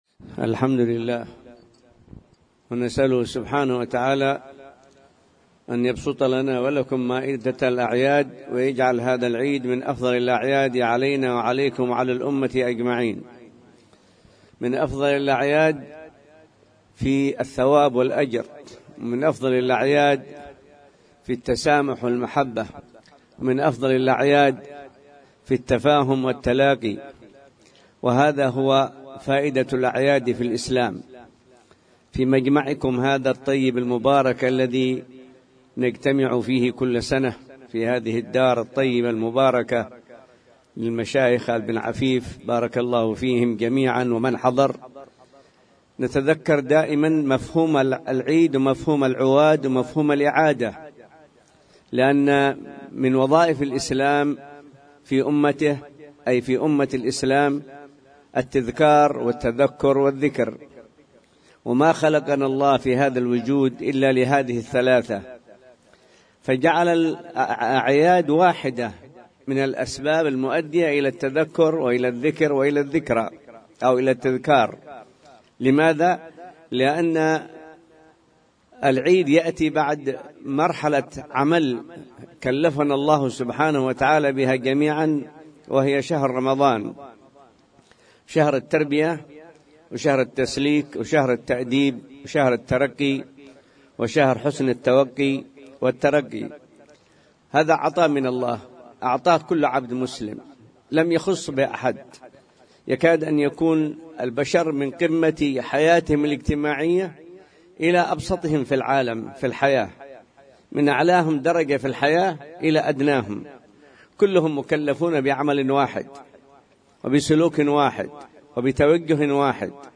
كلمة
في عواد آل الهجرين بمناسبة عيد الفطر المبارك لعام 1437هـ